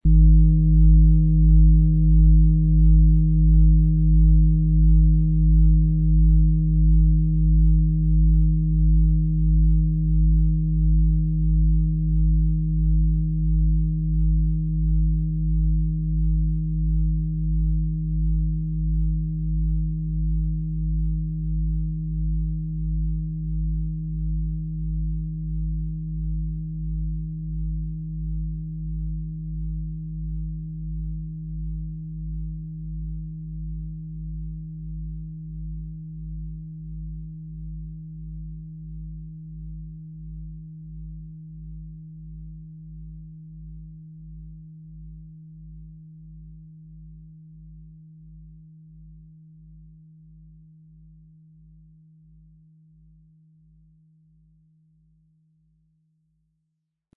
XXXL Fußreflexzonenschale mit Planetenton Eros - folge dem Klang der Liebe - Ø 50 cm und 10,77 kg, bis Schuhgröße 46, mit Klöppel
Ihre weichen, sinnlichen Schwingungen laden dich ein, dich selbst mit neuen Augen zu sehen – liebevoll, verbunden und offen.
Um den Originalton der Schale anzuhören, gehen Sie bitte zu unserer Klangaufnahme unter dem Produktbild.
PlanetentonEros & Eros (Höchster Ton)
MaterialBronze